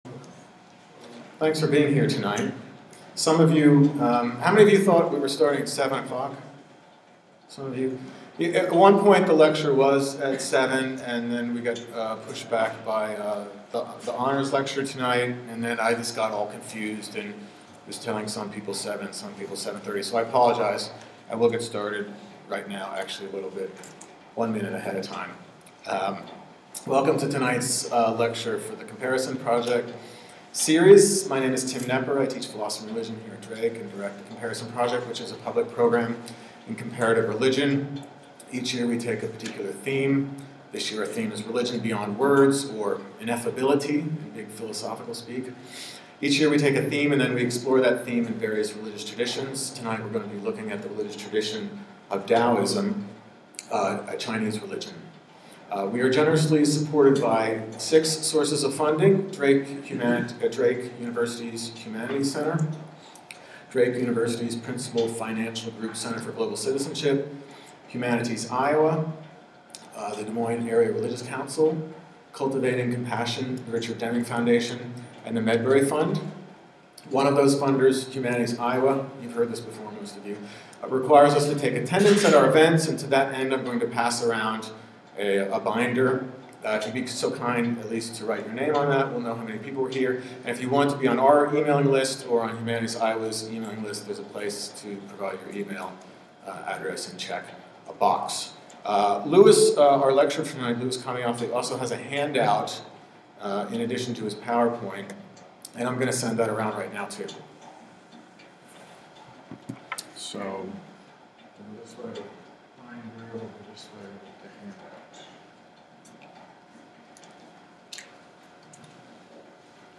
Lecture
Thursday, March 6, 7:30 p.m., Cowles Library Reading Room